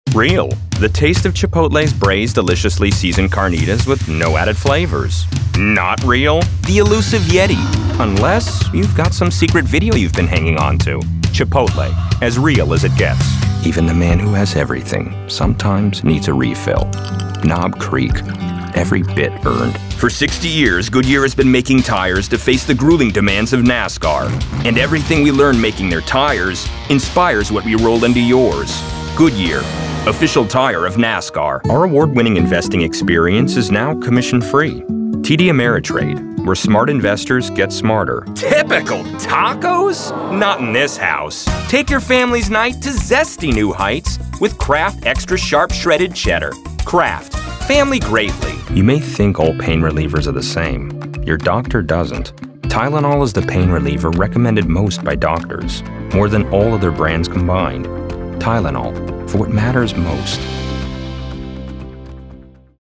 Mature Adult, Adult Has Own Studio
VOICEOVER GENRE commercial